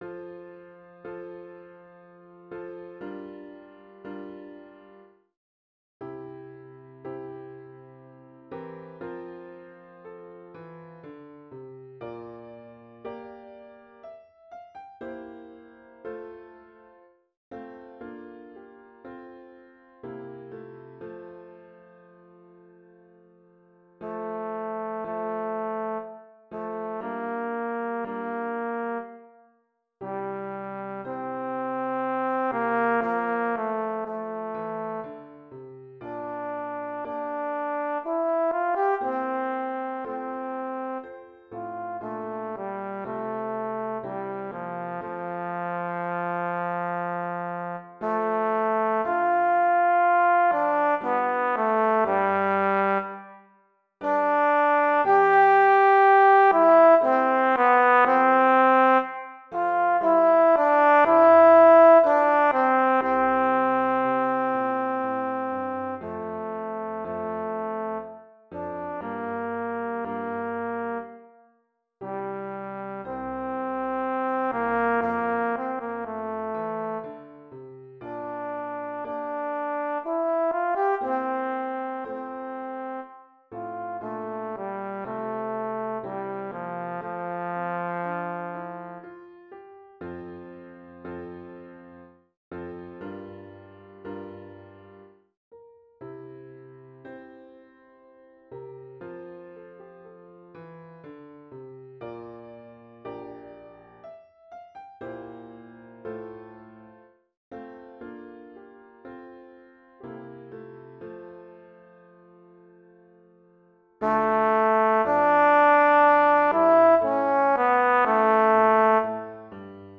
Voicing: Trombone